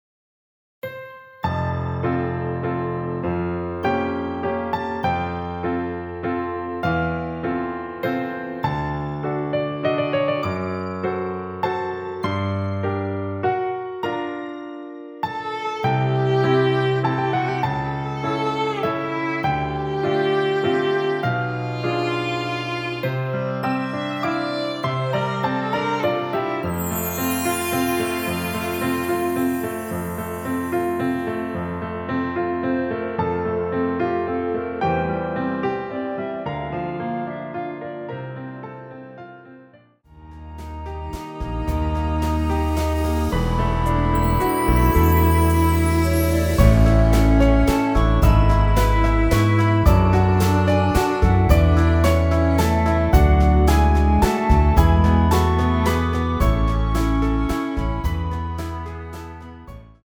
원키에서(-1)내린 멜로디 포함된 MR입니다.
앞부분30초, 뒷부분30초씩 편집해서 올려 드리고 있습니다.
중간에 음이 끈어지고 다시 나오는 이유는